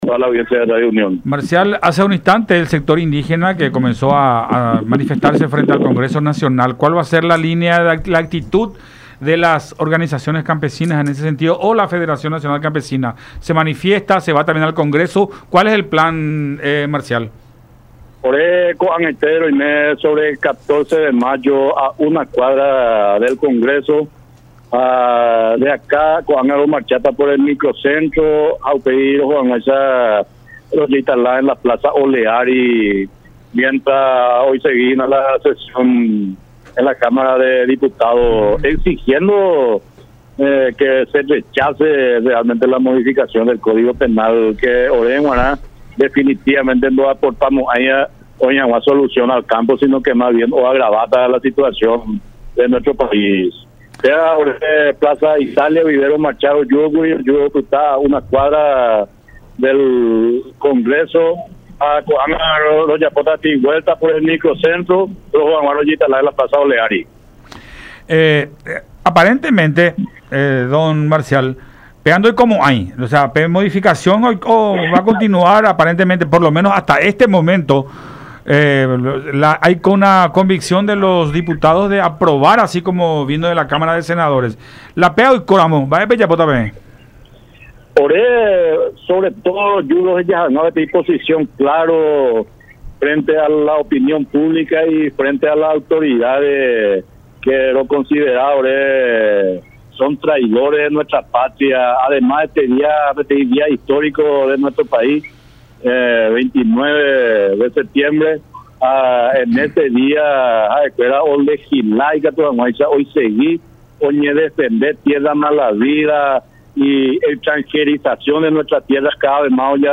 en conversación con Enfoque 800 por La Unión.